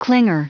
Prononciation du mot clinger en anglais (fichier audio)
Prononciation du mot : clinger